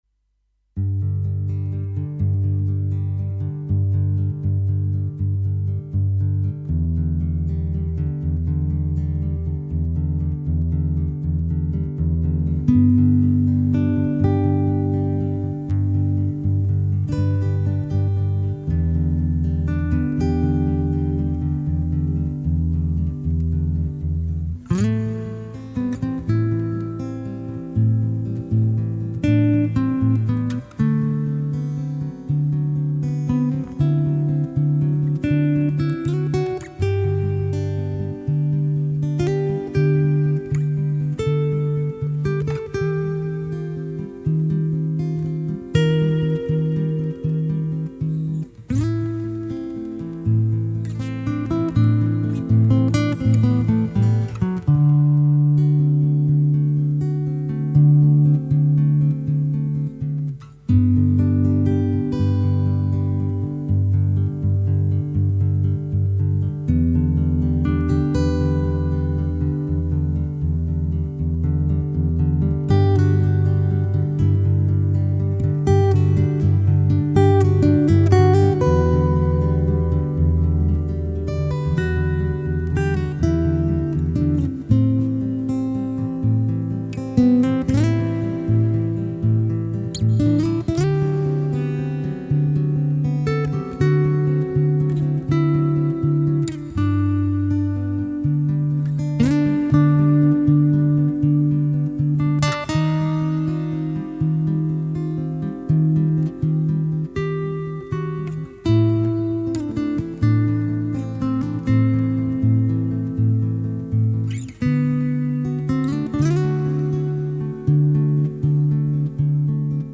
Una canción tocada para ser escuchada bajo el sol.